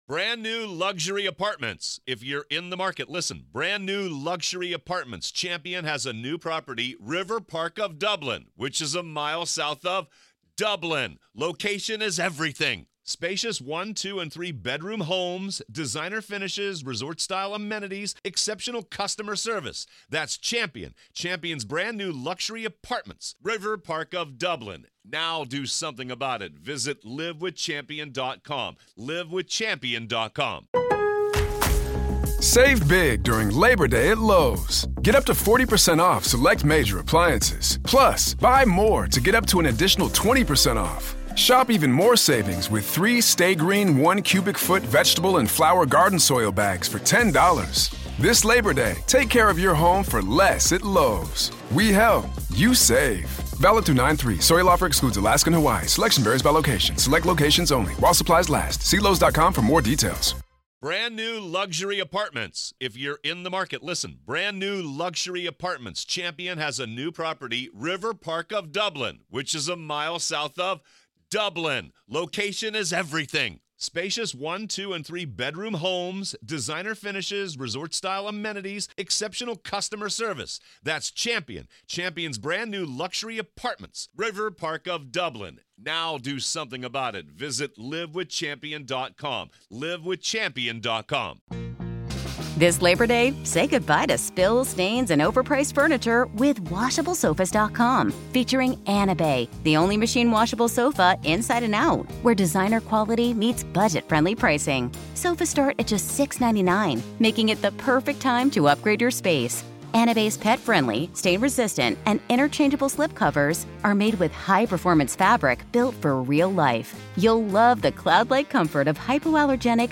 Experience the raw courtroom drama firsthand as we delve into the "Rust" movie shooting trial with unfiltered audio and expert analysis.
Go beyond the headlines: Hear the emotional pleas and heated arguments directly from the courtroom. Gain insider insights from legal experts as they break down the complex charges, the defense strategy, and the potential impact on the industry.